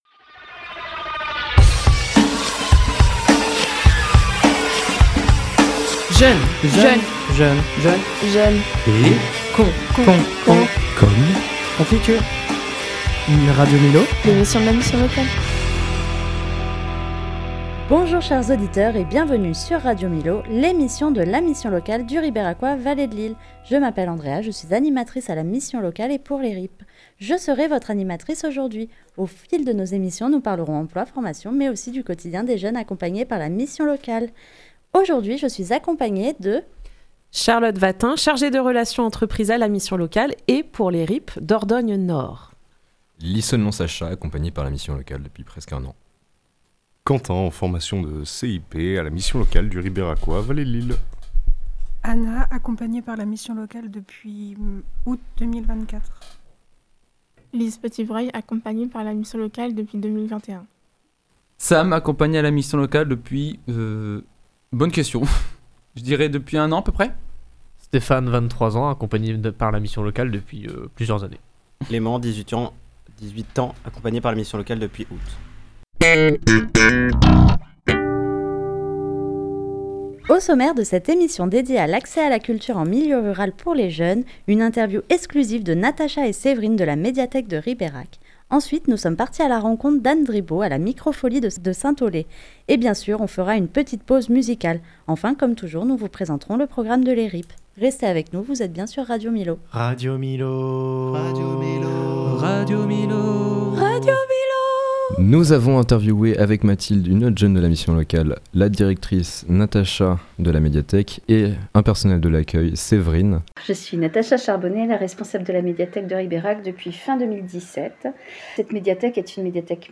La mission locale et des jeunes prennent le micro.